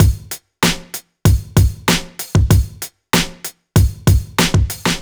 Track 14 - Drum Break 04.wav